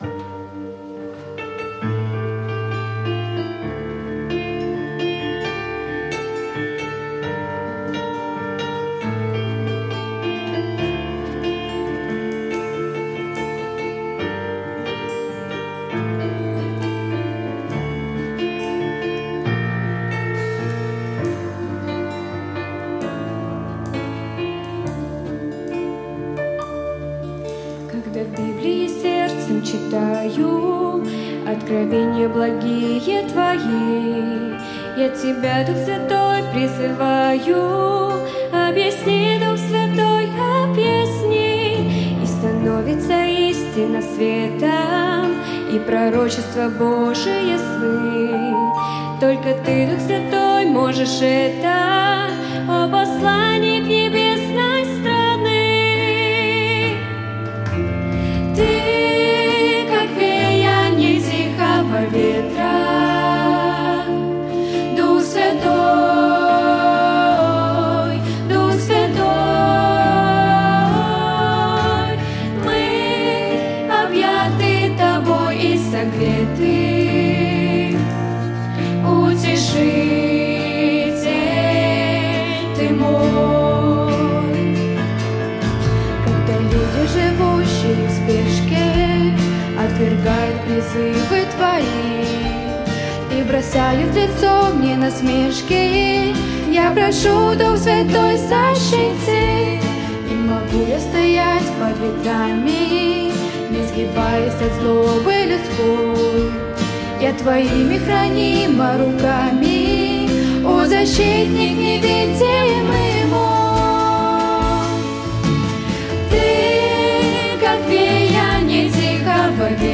Троица (второй день) - 9 июня 2025